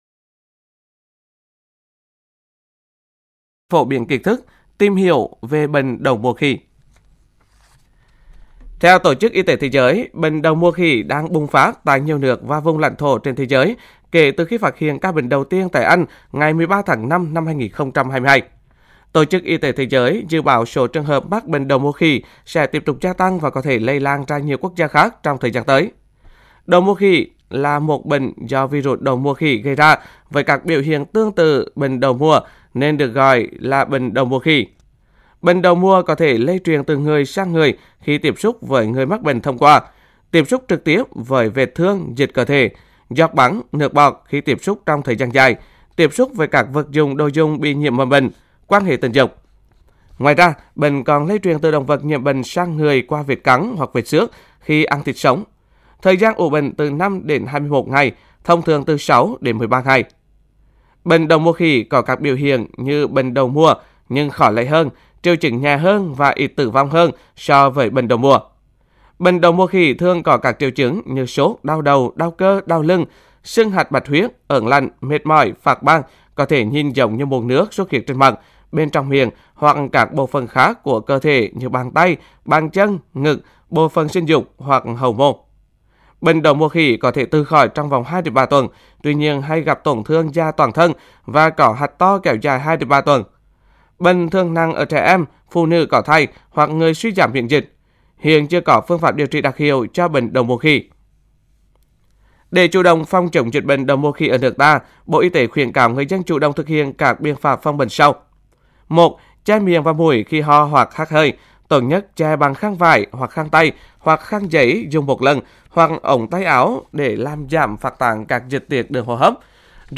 Phát thanh phòng chống bệnh đậu mùa khỉ